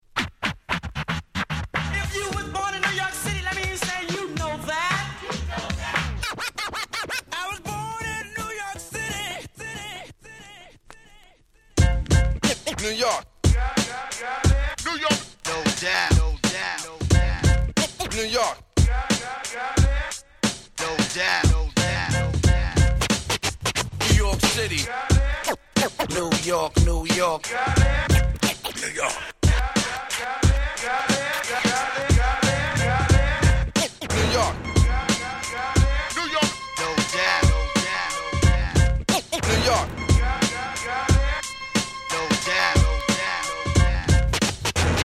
90's Boom Bap